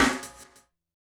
snare01.wav